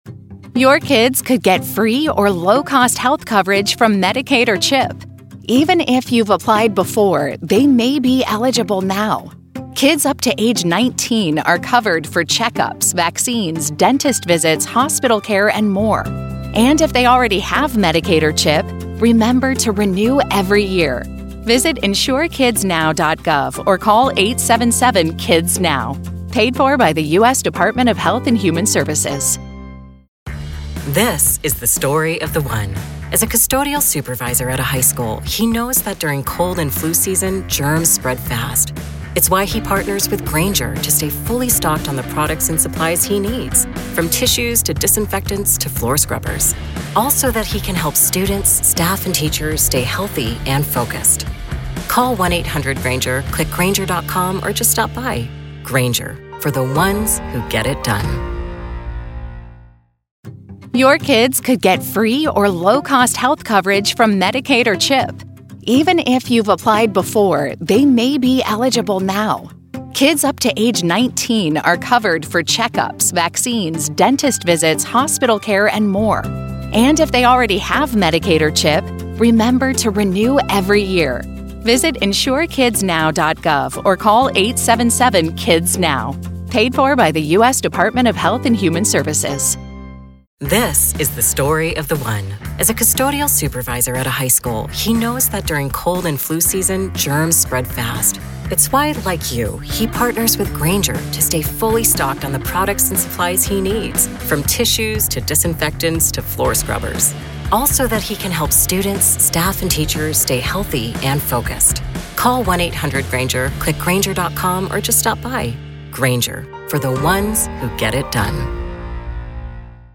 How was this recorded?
RAW COURT AUDIO